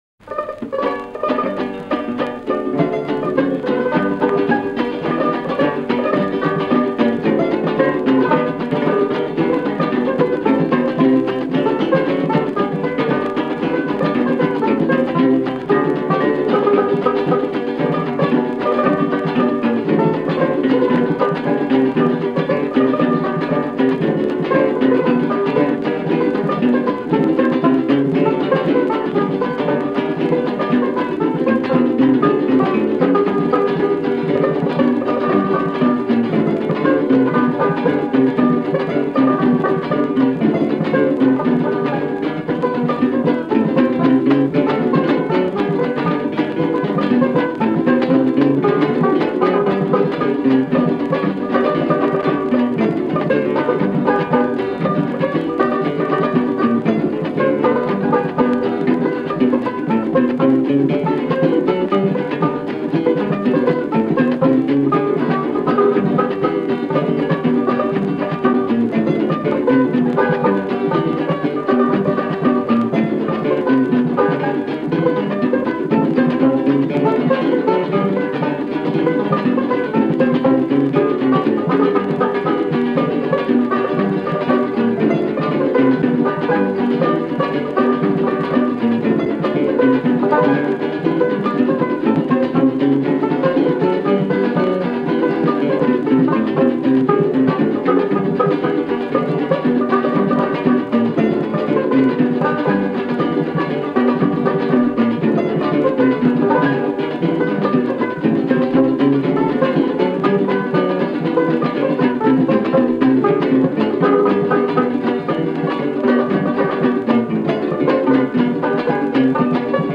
Esta versión instrumental la grabó en Londres en 1929